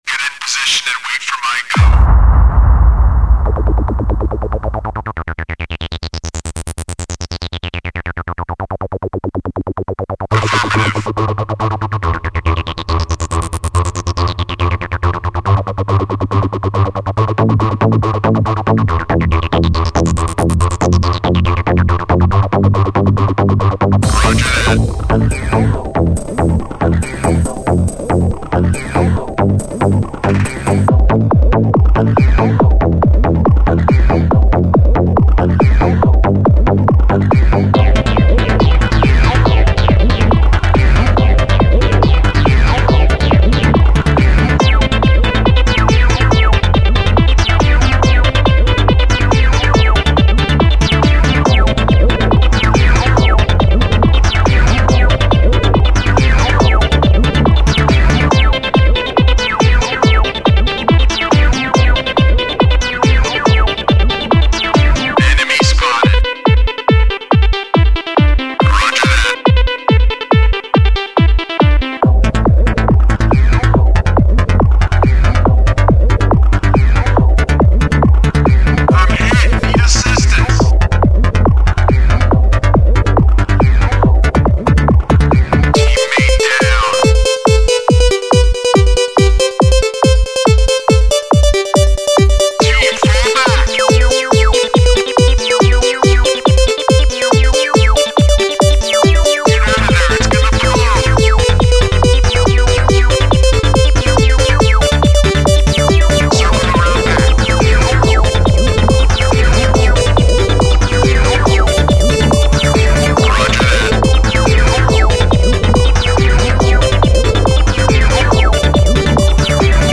Techno Всего комментариев